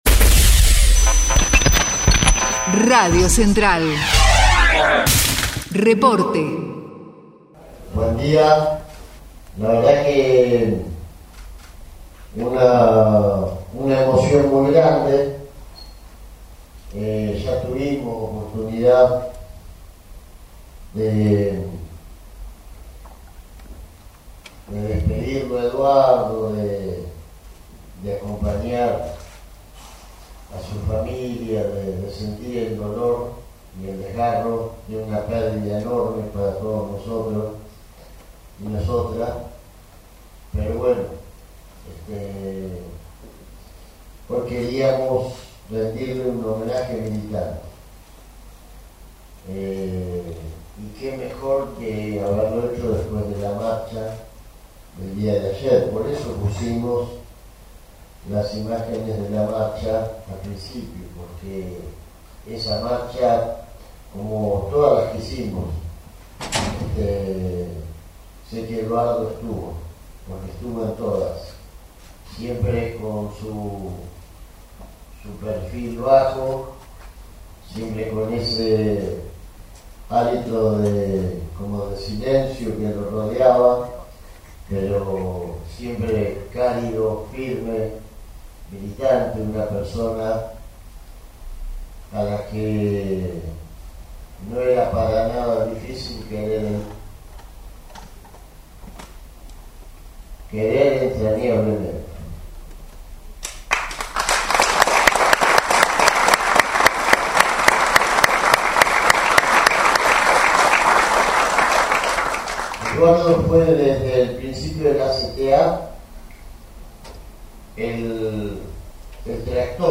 Testimonio de Hugo Yasky